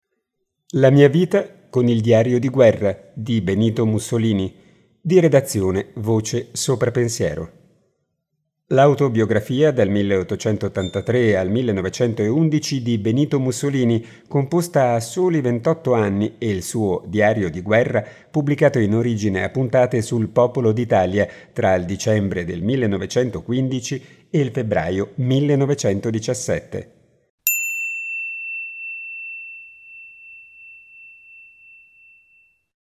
Dall’incipit del libro: